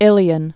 (ĭlē-ən, -ŏn)